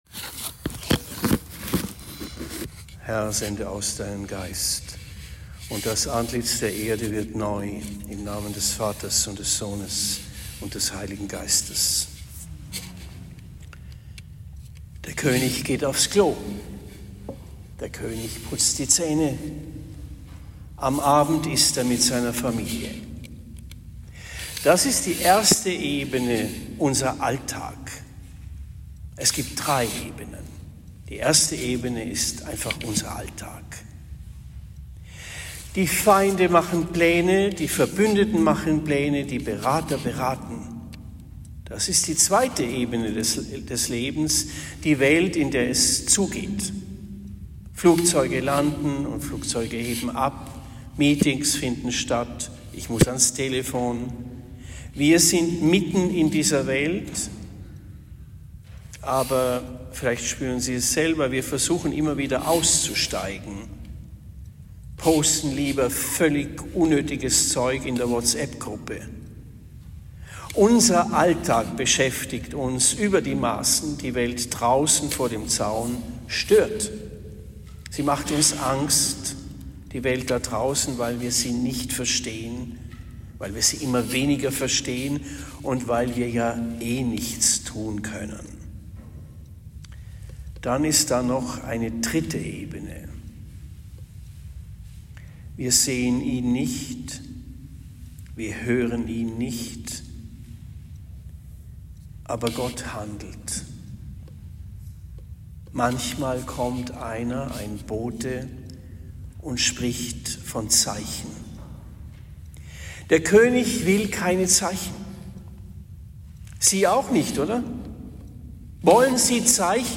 Predigt in Windheim am 20. Dezember 2025